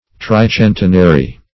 Tricentenary \Tri*cen"te*na*ry\, a. [Pref. tri- + centenary.]